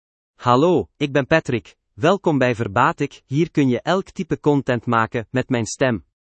Patrick — Male Dutch (Belgium) AI Voice | TTS, Voice Cloning & Video | Verbatik AI
Patrick is a male AI voice for Dutch (Belgium).
Voice sample
Male
Dutch (Belgium)
Patrick delivers clear pronunciation with authentic Belgium Dutch intonation, making your content sound professionally produced.